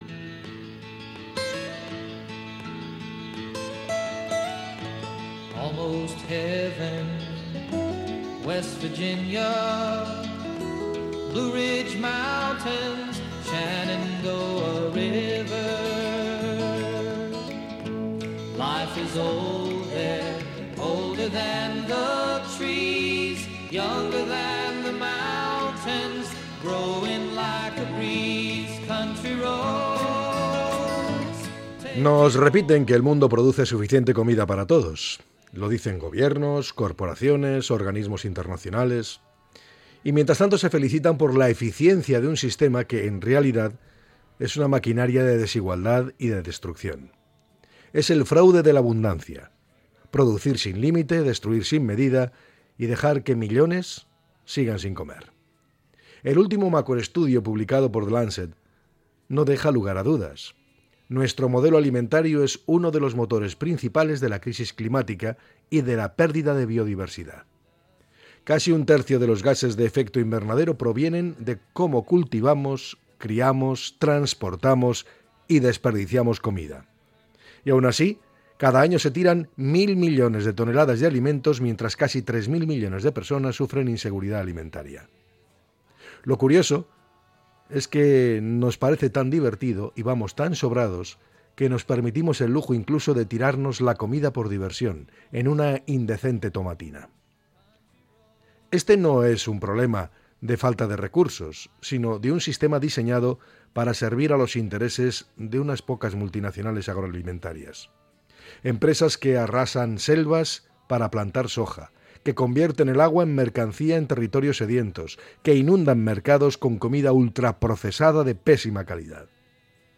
Podcast Opinión